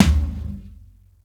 Tom A02.wav